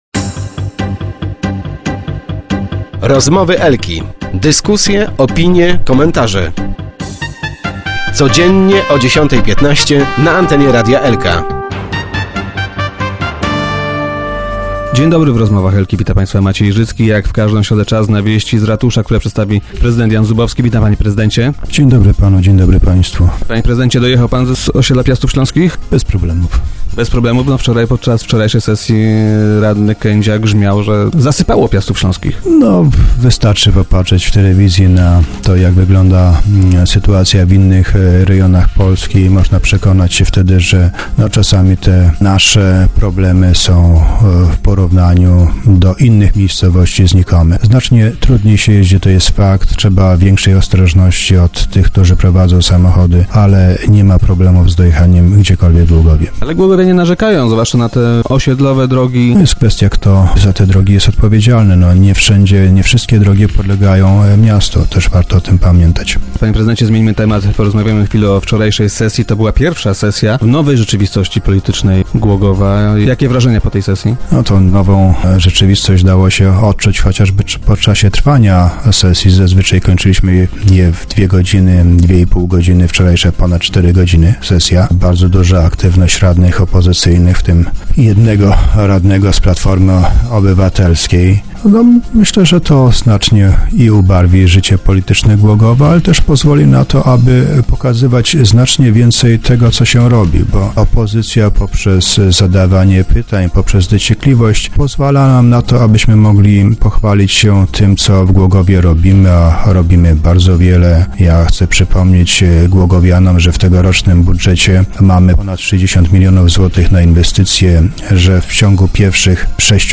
thumb_0204_prezydent.jpgGłogów. Wczorajsza sesja rady miasta była pierwszą od czasu rozpadu koalicji Platformy Obywatelskiej i PiS-u. Opozycja powiększyła się o radnych PO i jak powiedział podczas dzisiejszych Rozmów Elki prezydent Jan Zubowski, dało to się odczuć.
Nie potrafię zrozumieć o czym myślą radni SLD, protestując przeciwko tej budowie - mówił podczas spotkania w radiowym studio prezydent.